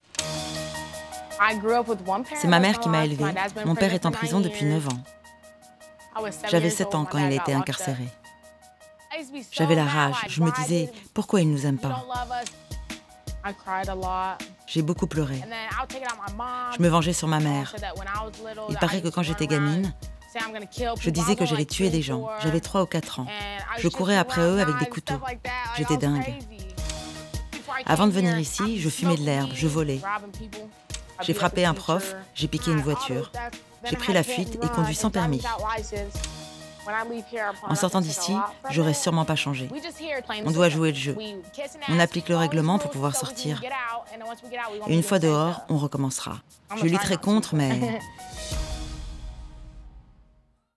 Doublage documentaire, jeune fille en prison
Doublage-jeune-fille-en-prison.wav